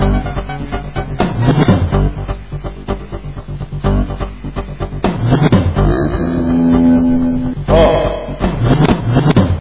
spooky